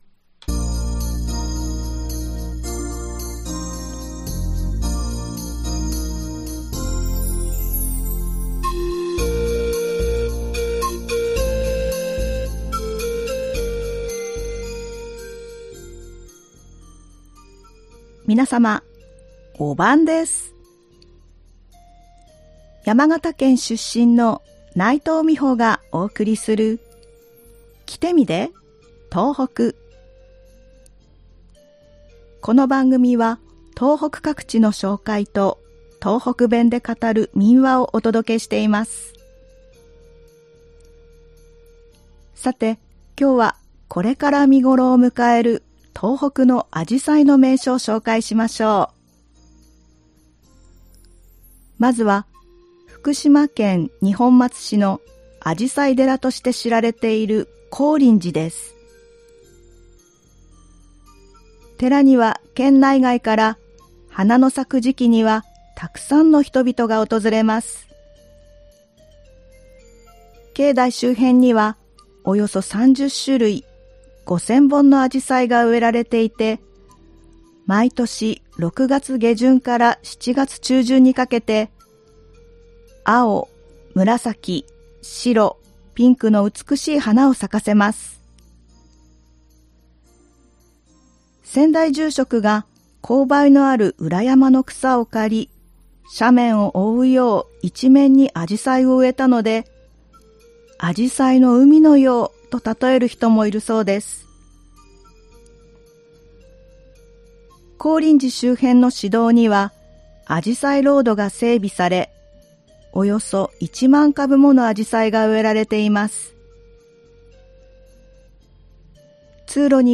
この番組は東北各地の紹介と、東北弁で語る民話をお届けしています。